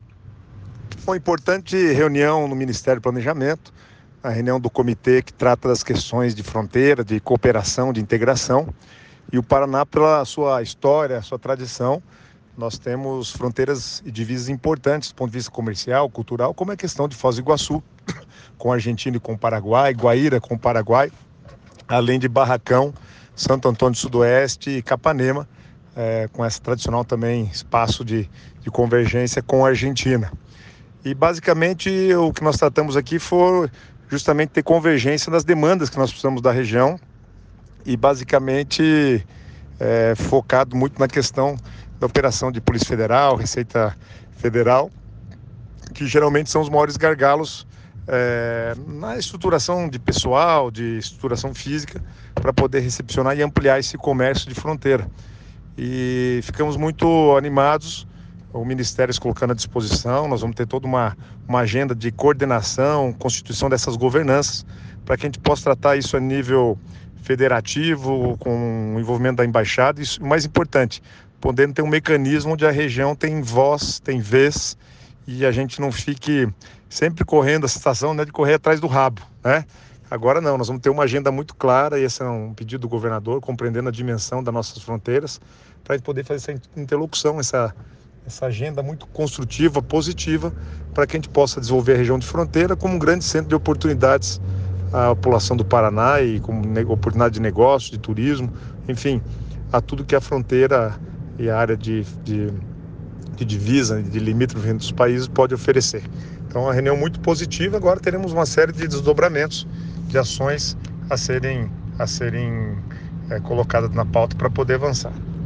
Sonora do secretário de Estado do Planejamento, Guto Silva, sobre reunião em Brasília